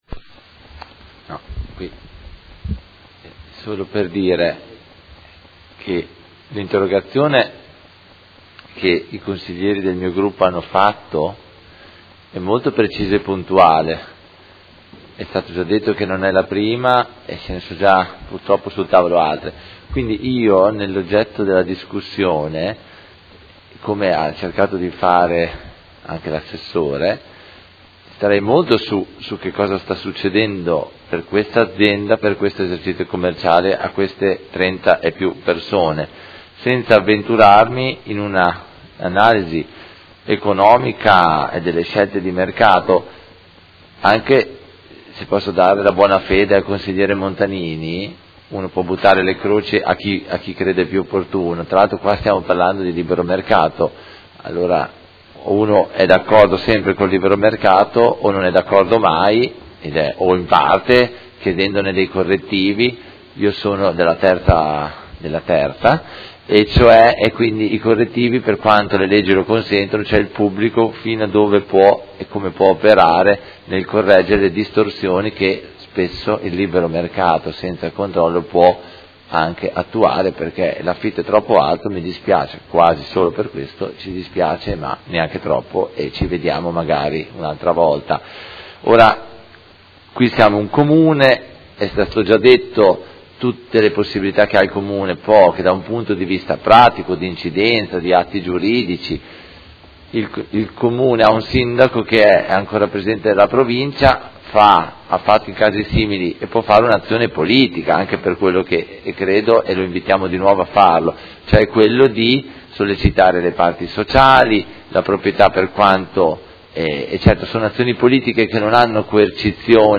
Antonio Carpentieri — Sito Audio Consiglio Comunale
Seduta del 1/12/2016 Interrogazione dei Consiglieri Malferrari, De Lillo e Bortolamasi (P.D.) avente per oggetto: Chiude il Flunch del Grandemilia, annunciati licenziamenti collettivi dei dipendenti. Dibattito